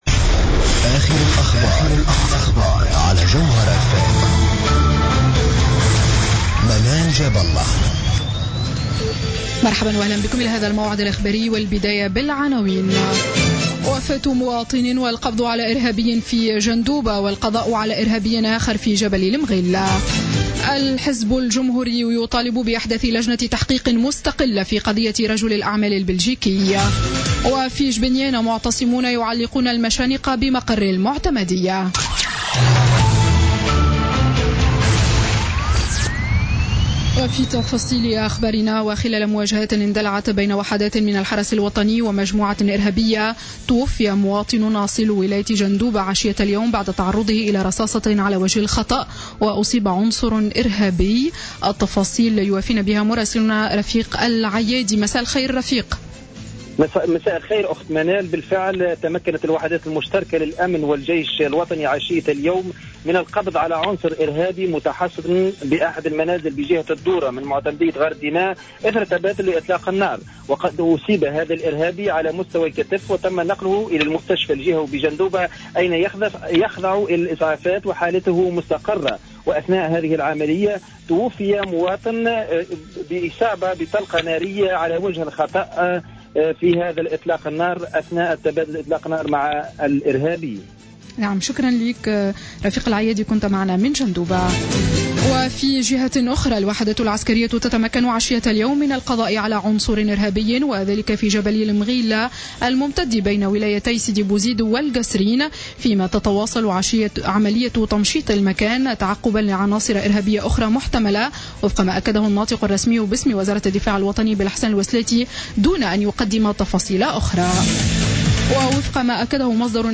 نشرة أخبار السابعة مساء ليوم الاثنين 22 فيفري 2016